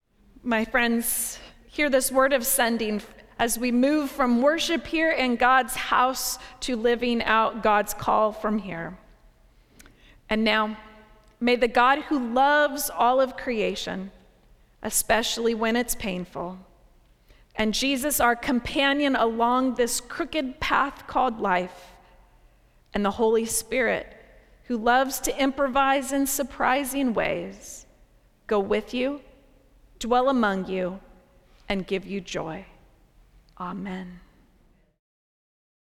Service of Worship
Benediction
benediction.mp3